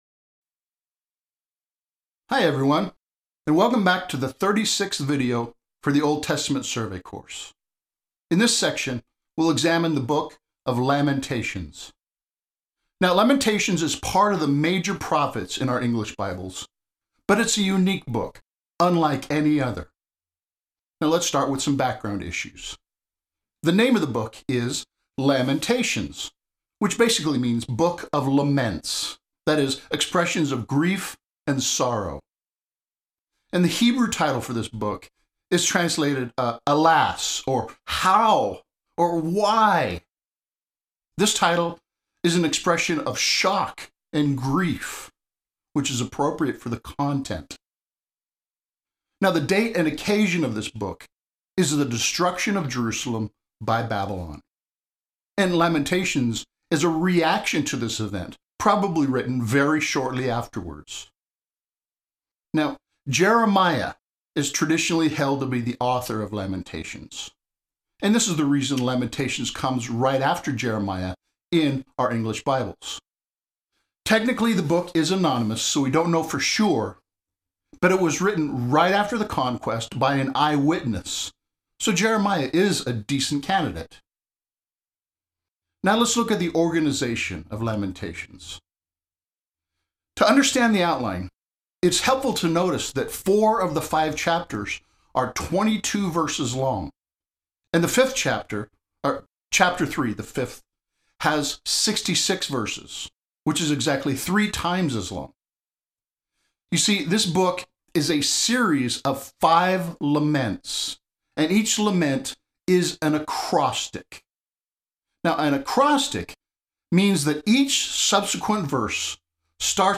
The second section has a survey video lecture covering the entire book.